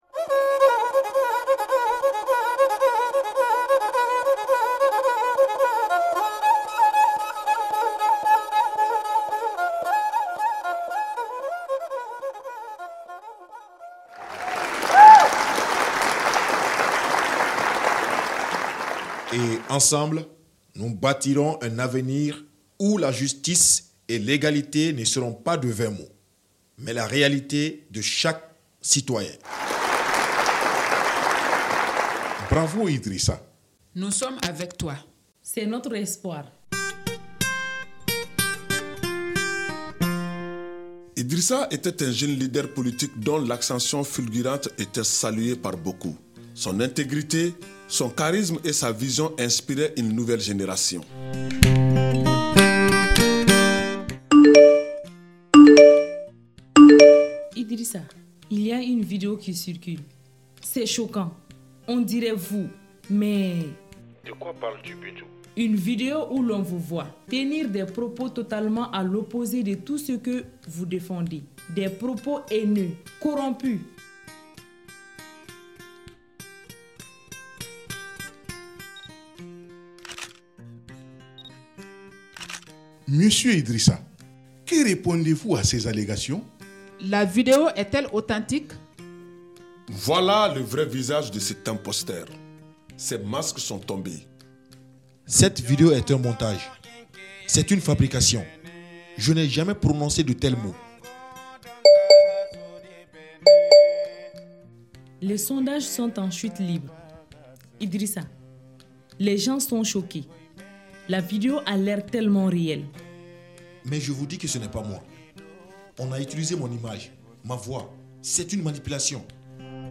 Dans ces épisodes, des journalistes et spécialistes de la vérification de l’information nous plongent au cœur des enjeux de la désinformation.